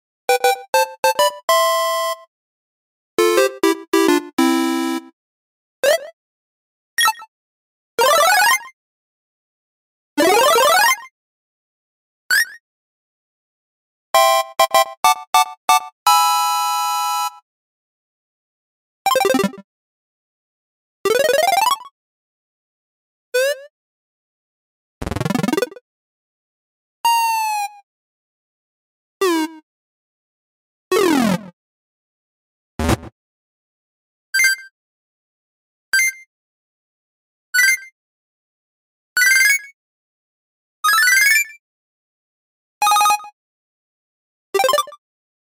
Восьмибитные звуки из ретро видеоигр для монтажа видео и просто вспомнить детство.
2. Второй сборник восьмибитных звуков